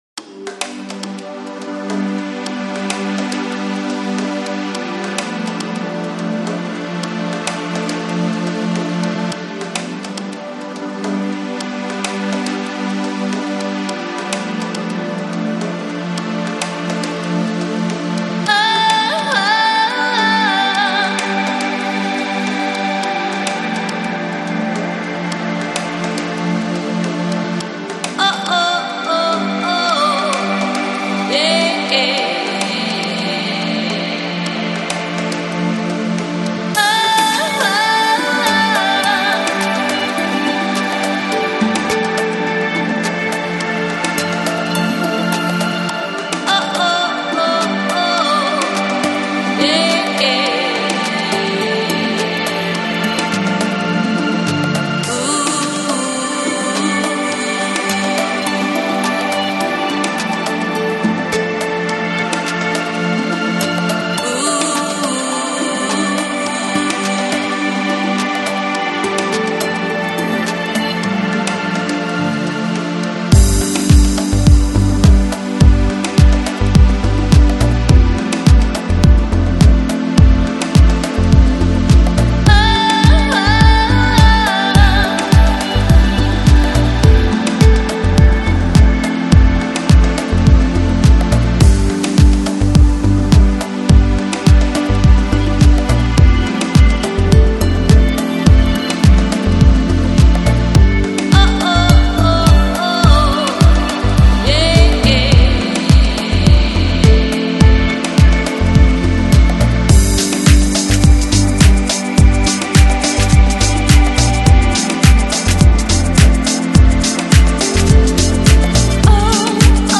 Electronic, Lounge, Chill Out, Balearic, Downtempo